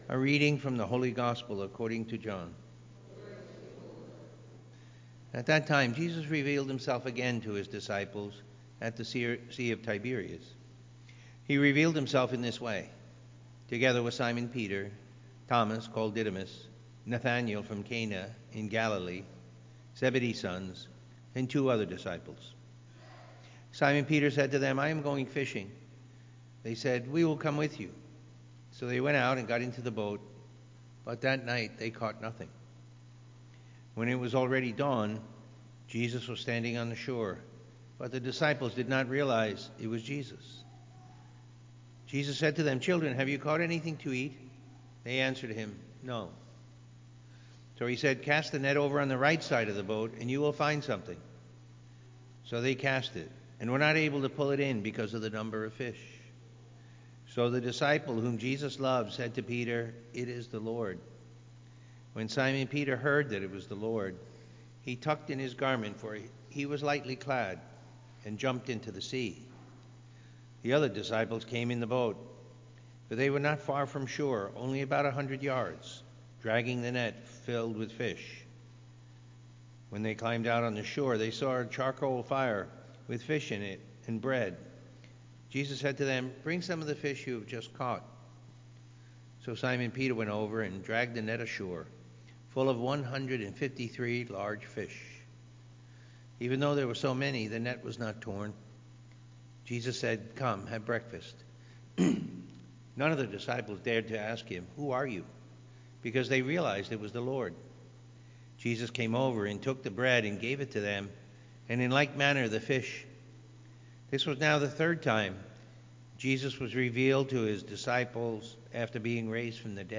Listen to the homily from the Sunday Mass and meditate on the Word of God.